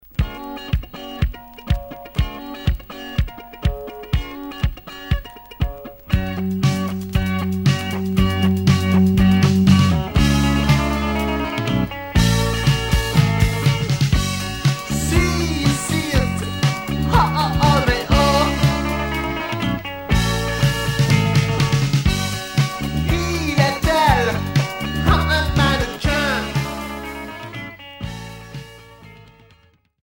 New wave